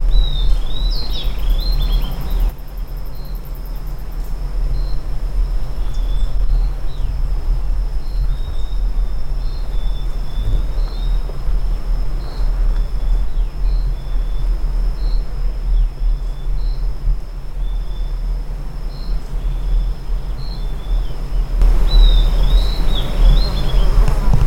Estalador (Corythopis delalandi)
Nome em Inglês: Southern Antpipit
País: Argentina
Província / Departamento: Salta
Condição: Selvagem
Certeza: Observado, Gravado Vocal